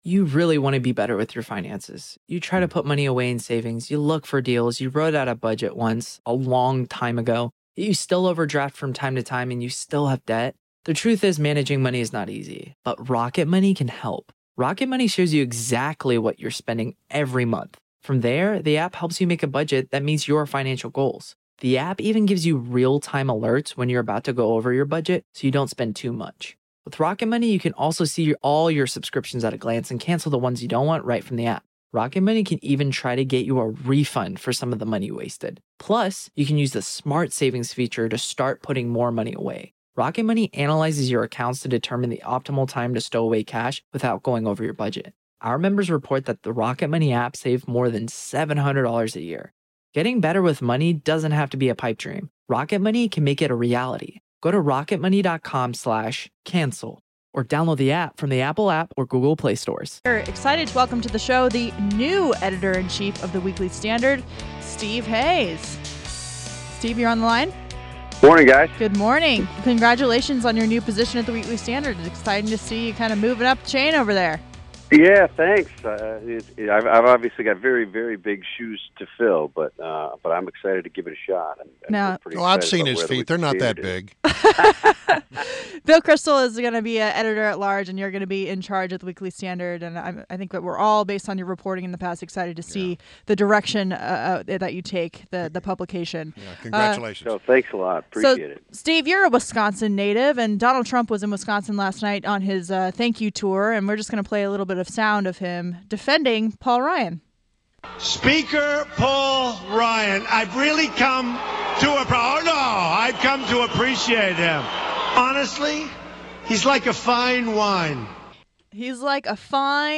WMAL Interview - STEVE HAYES - 12.14.16